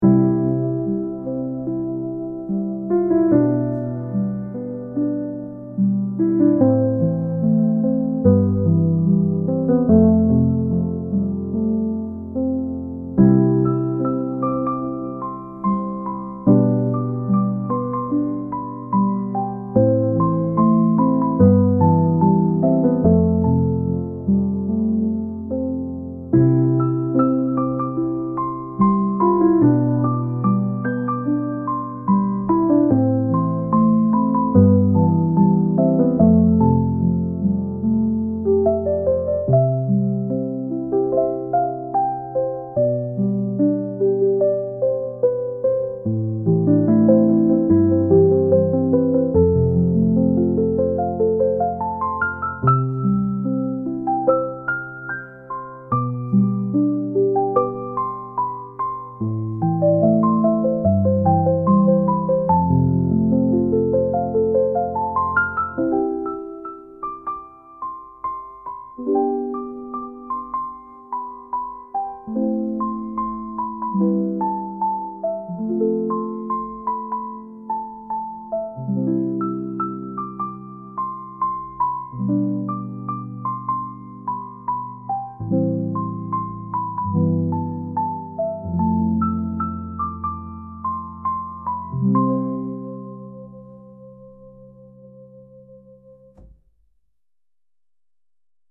Bakgrundsmusiken är här för att förstärka känslan av texterna.